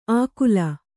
♪ ākula